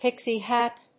アクセントpíxie hàt [hòod]